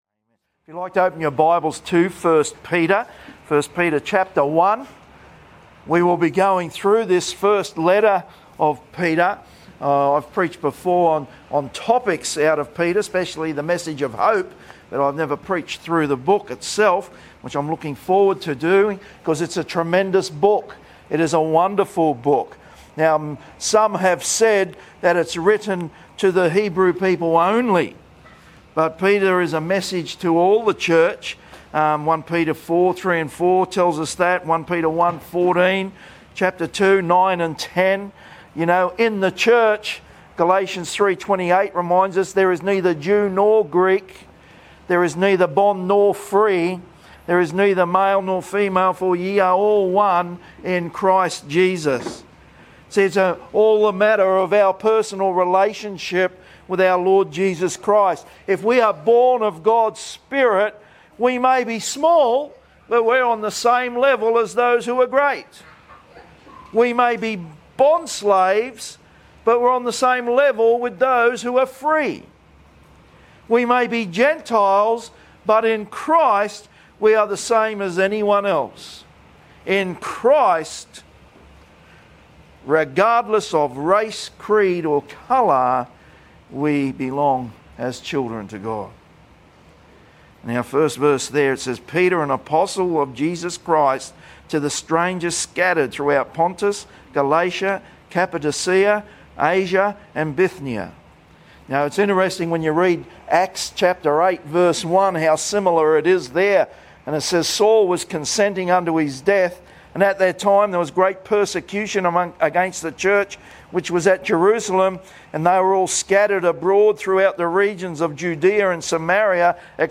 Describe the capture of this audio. at the Gold Coast